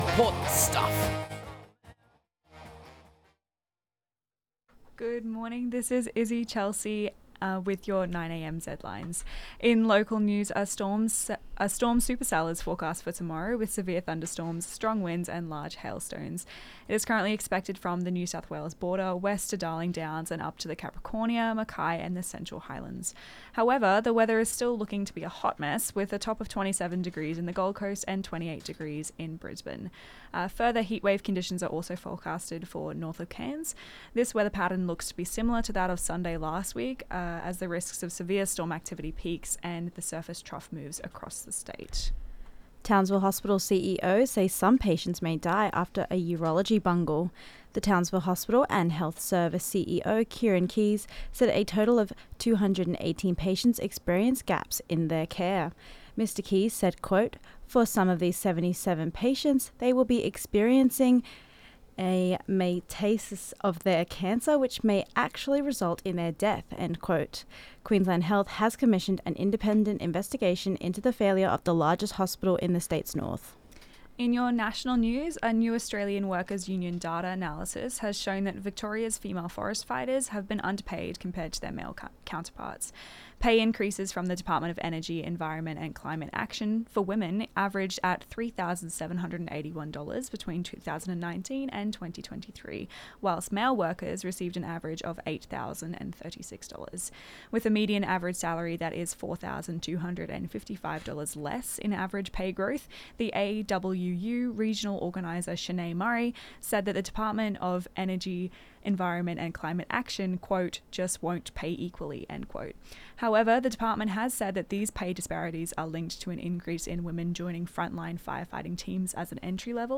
Zedlines Bulletin